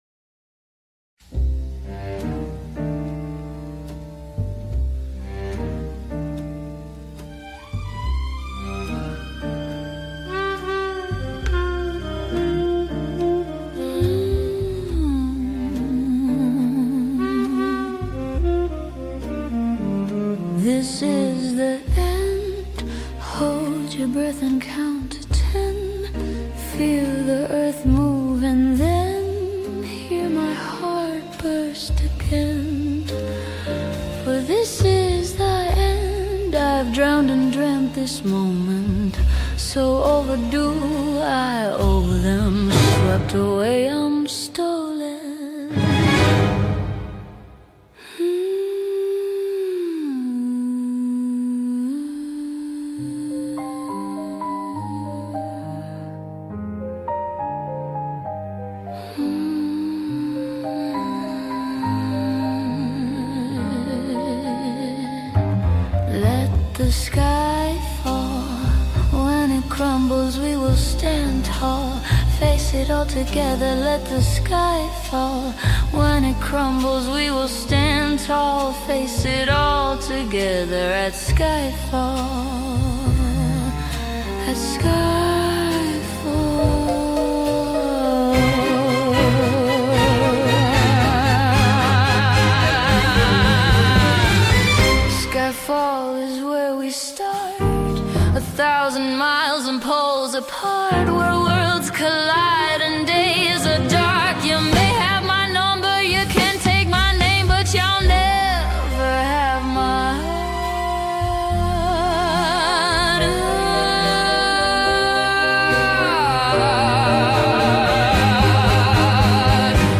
Almost 1950s